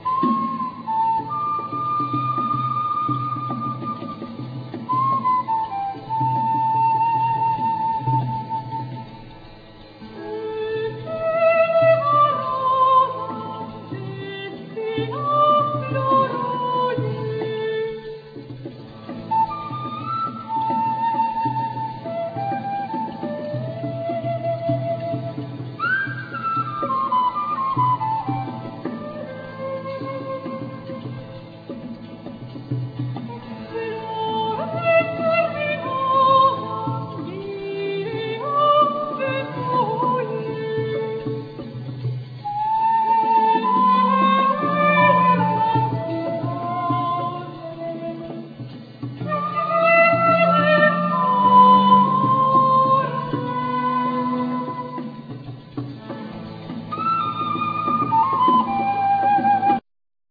Flute,Recorder,Saxophone,Clarinets,Chalumeaux
Percussions
Soprano
Alto
Tenor
Bass
Viola da Gamba
Cembalo,Regal